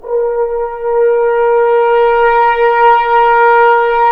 Index of /90_sSampleCDs/Roland L-CD702/VOL-2/BRS_Accent-Swell/BRS_FHns Swells